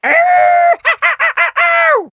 One of Diddy Kong's voice clips in Mario Kart Wii